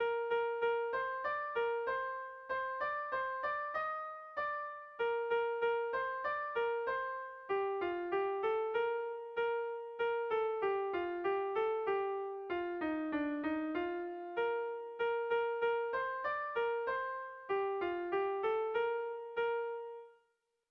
Dantzakoa
A1A2BA2